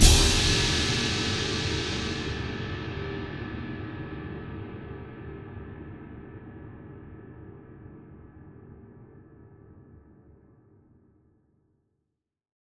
Big Drum Hit 28.wav